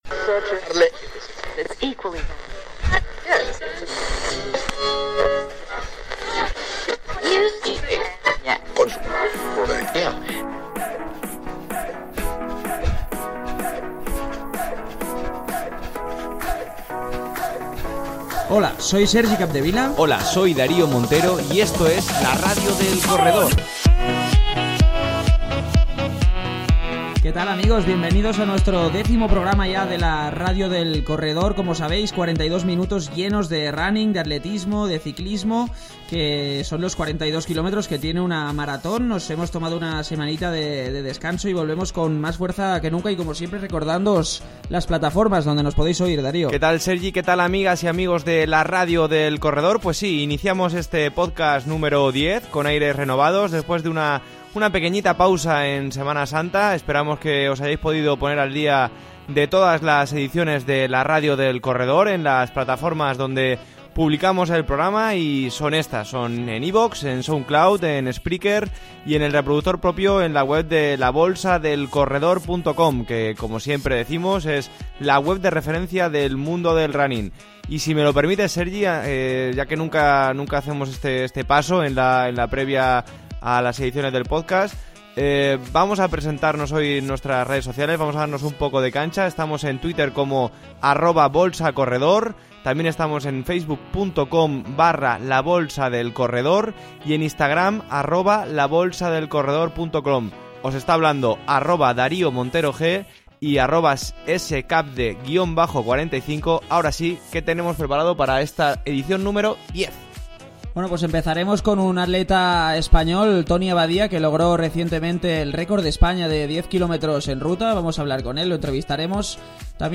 La Salomon Run, la entrevista a Toni Abadía y mucho más.